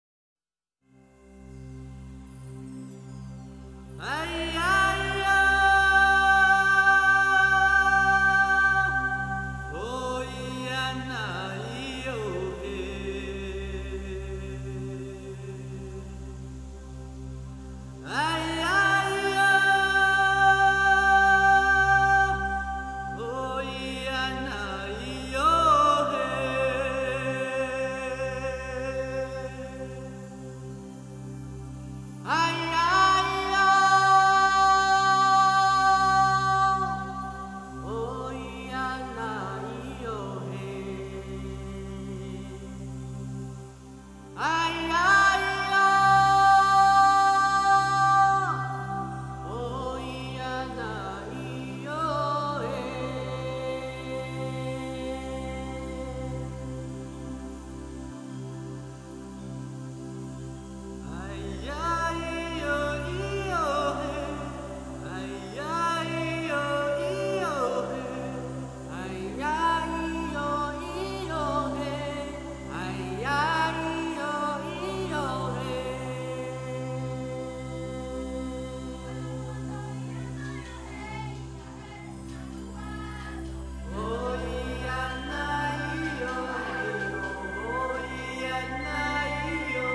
遇見另一角落的童聲吟唱
原住民青少年兒童合唱音樂的收錄珍
台中縣花東、自強新村原住民與平地小孩的聯合演唱